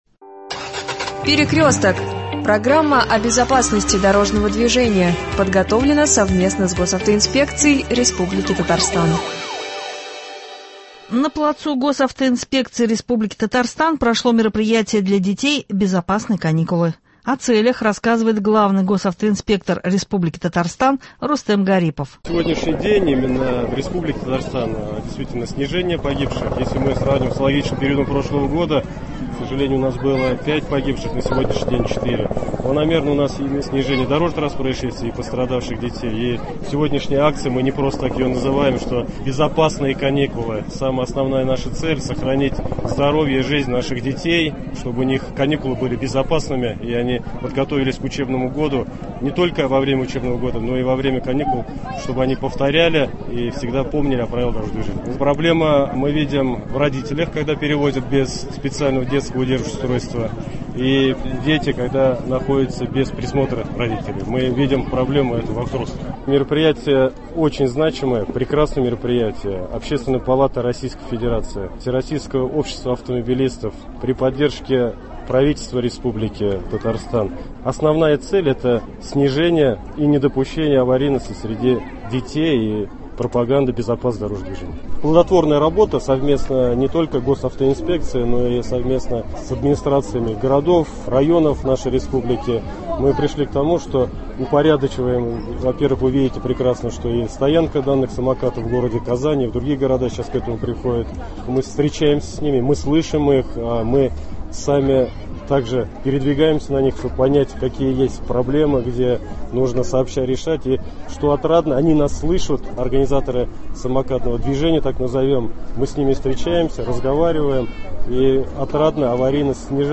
На плацу Госавтоинспекции республики Татарстан прошло мероприятие для детей «Безопасные каникулы». О целях рассказывает главный госавтоинспектор республики Рустем Гарипов.